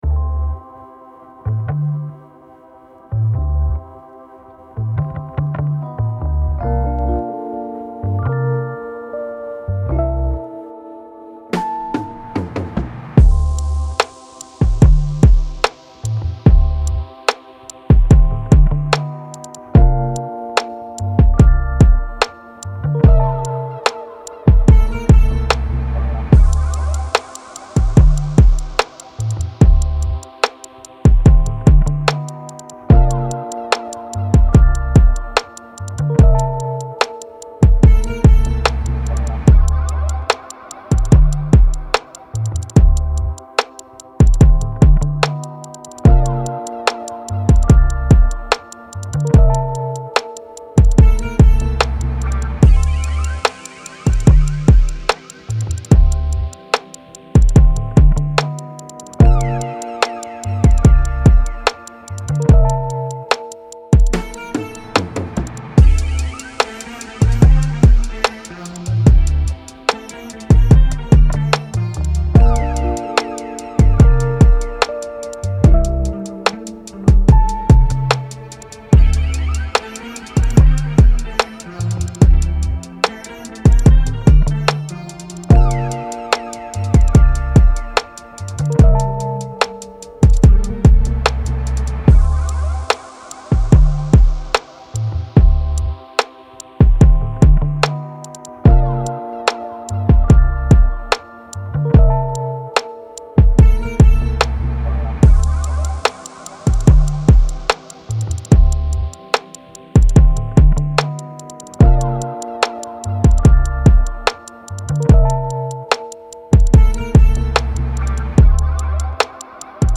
Soul, R&B, Lofi, Hip Hop
D Min